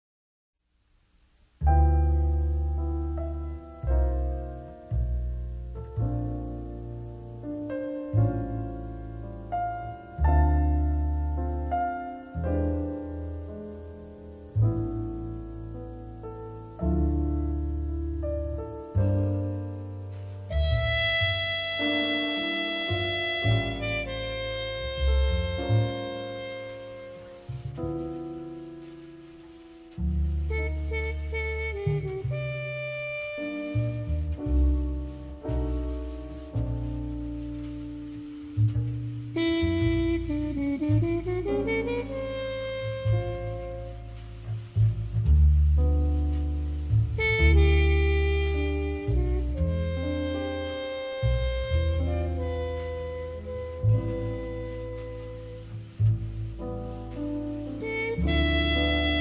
Le swing est intraitable, les harmonies magiques.